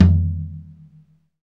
Index of /90_sSampleCDs/Sound & Vision - Gigapack I CD 1 (Roland)/KIT_REAL m 1-16/KIT_Real-Kit m 3
TOM TOM121.wav